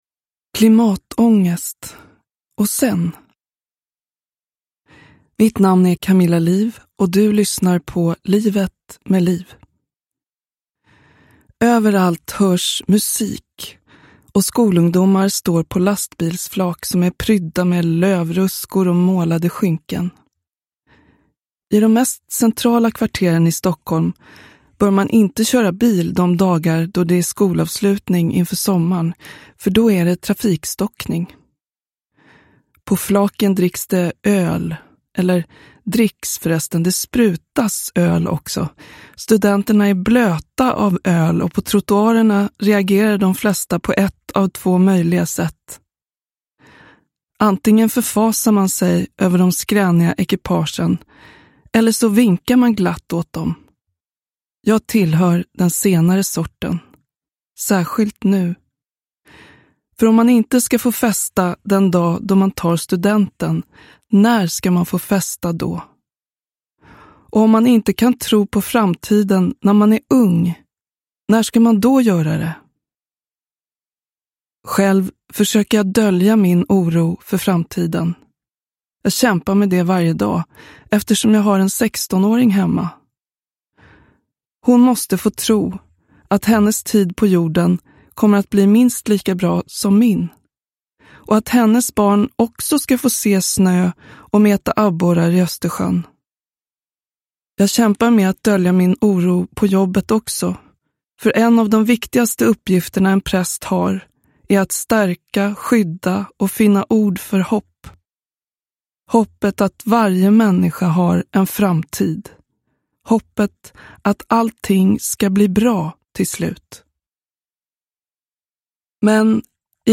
En stund med denna kloka röst hjälper dig att lyfta blicken och se varmare på dig själv och din omvärld.